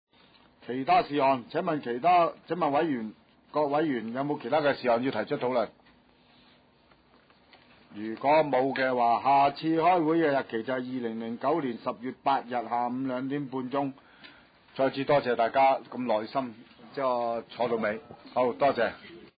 葵青民政事務處會議室